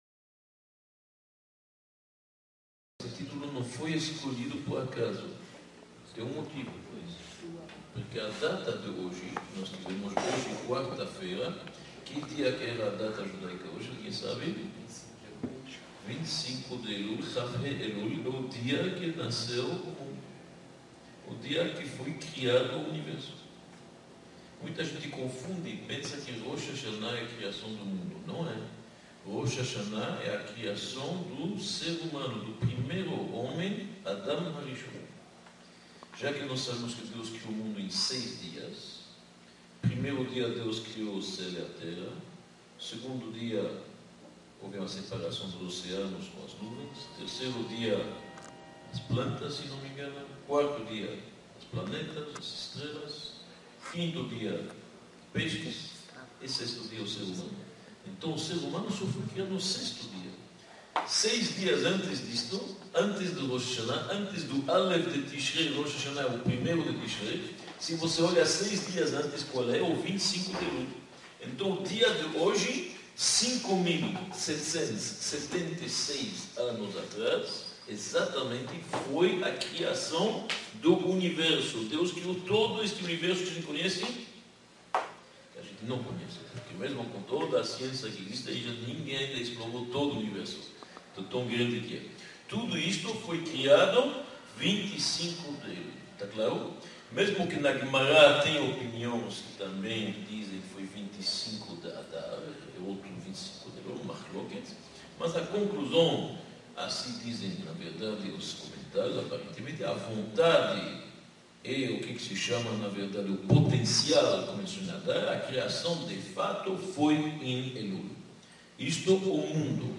Palestra-O-cenário-da-criação-se-repete-a-cada-ano-1.mp3